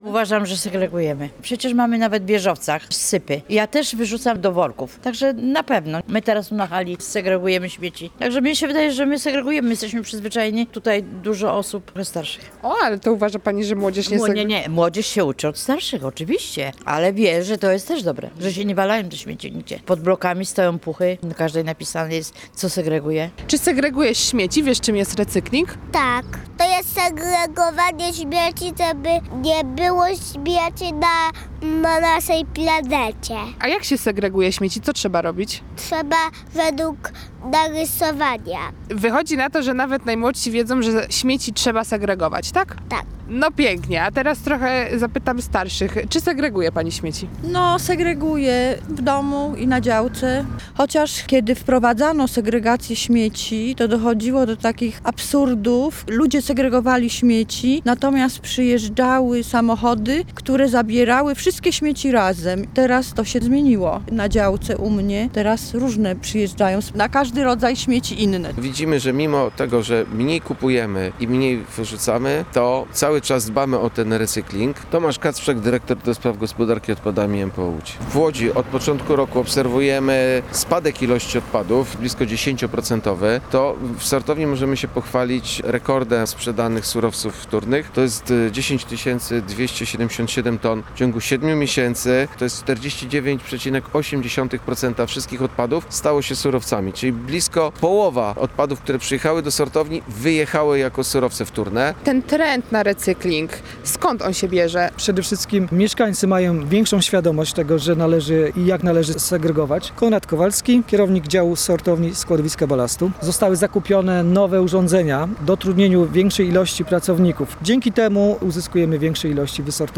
Na te pytania odpowiedzieli sami mieszkańcy.